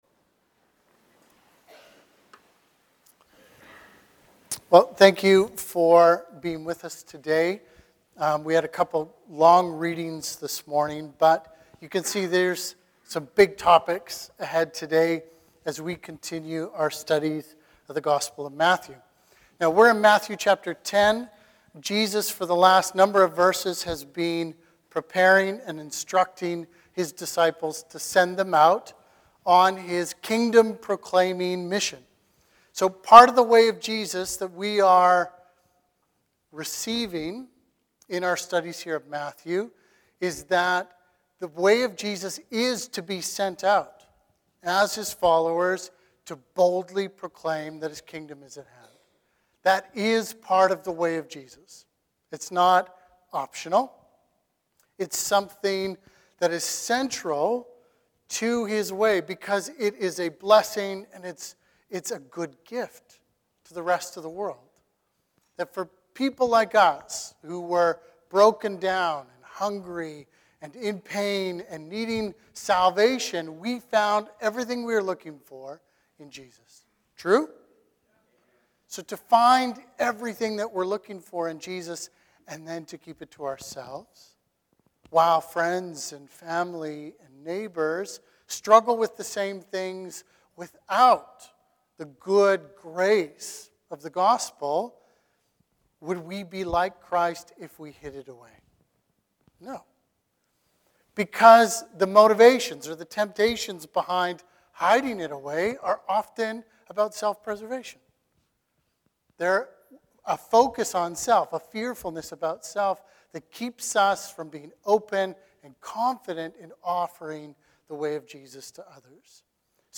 Sermons | Christ's Church Oceanside